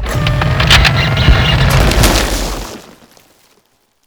iceload.wav